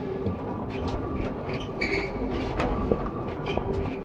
strand-casting-machine.ogg